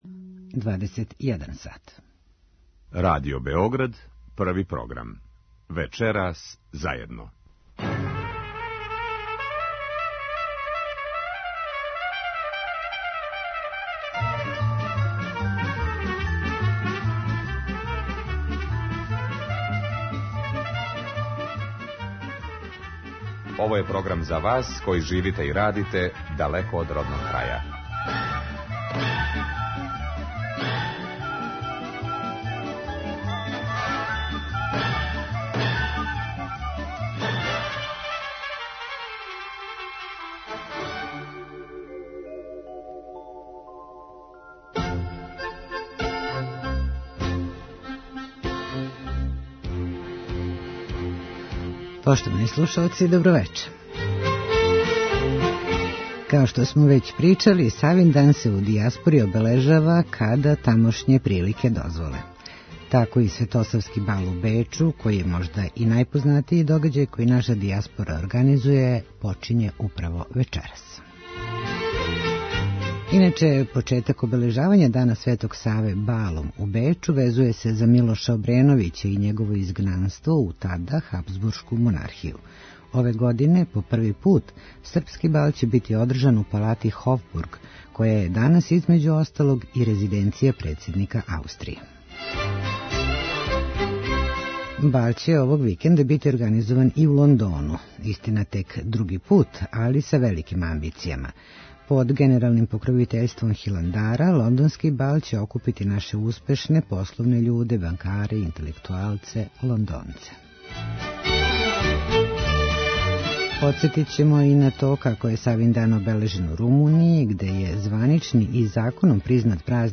Емисија магазинског типа која се емитује сваког петка од 21 час.
Подсетићемо вас, и музиком, на време српских балова, у европским престоницама.